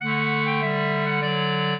clarinet
minuet0-3.wav